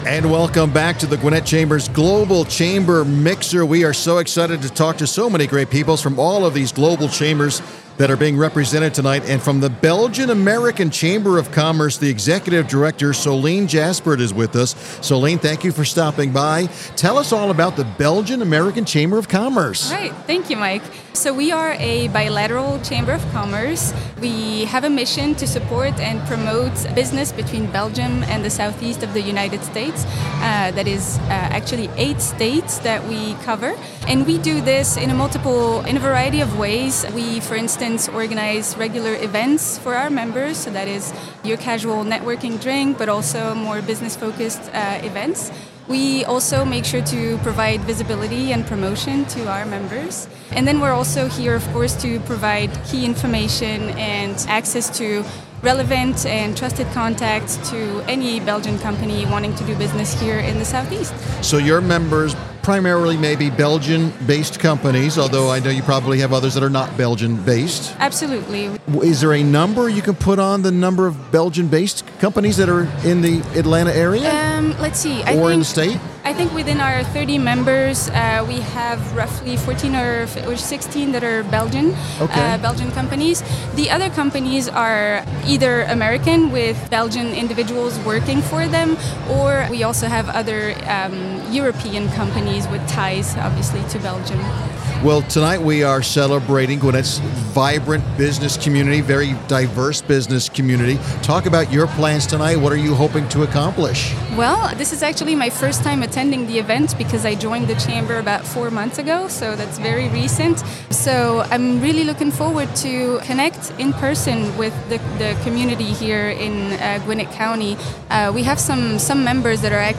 🎙 Check out this brief interview if you are curious to learn more about who we are and what we do at BACoS!